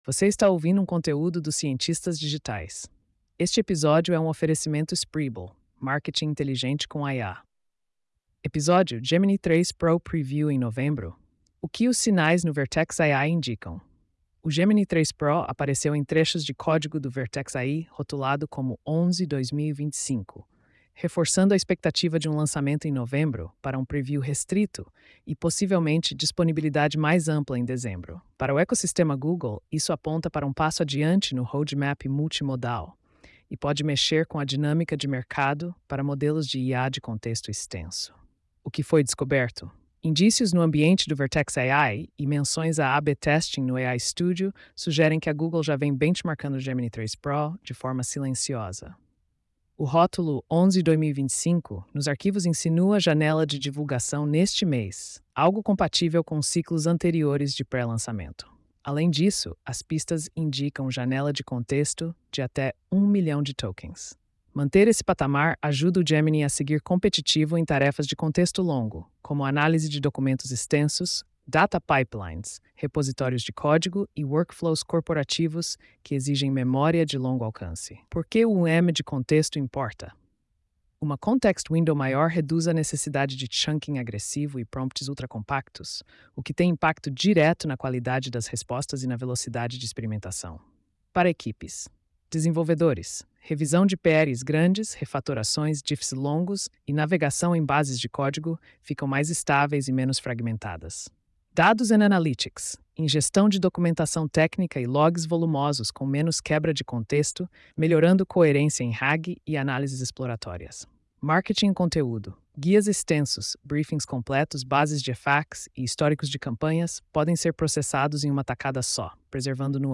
post-4510-tts.mp3